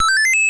coin.wav